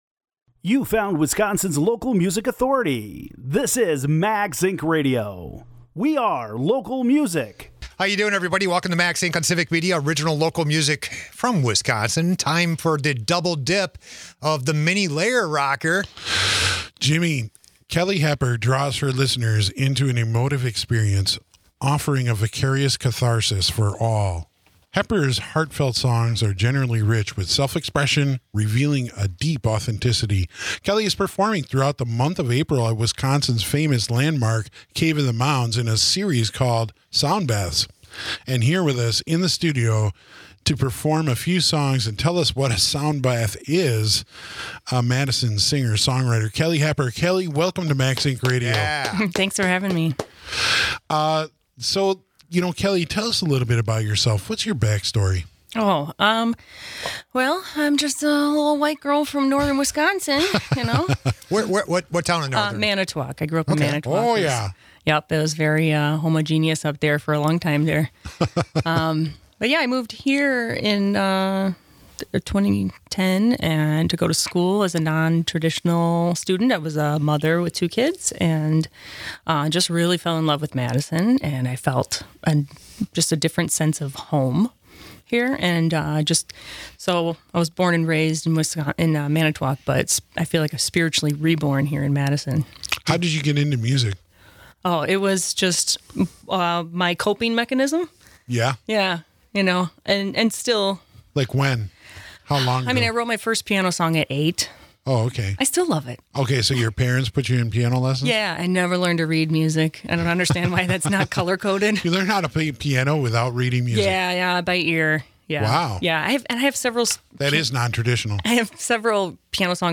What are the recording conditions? performs live in the Lair is in the Lair for performance and interview with new songs, COPA Jam experiences, Sound Baths at Cave of the Mounds and more.